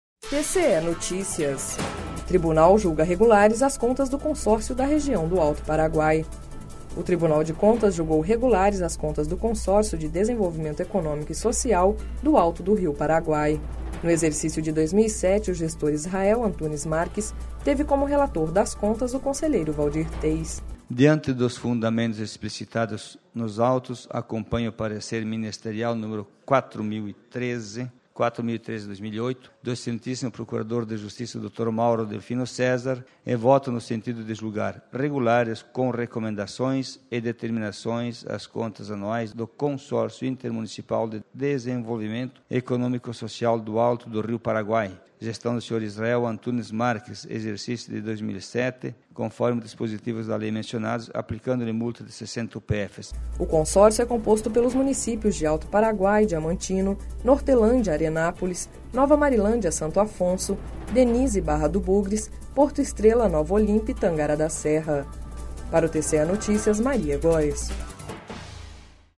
Sonora: Waldir Teis – conselheiro do TCE-MT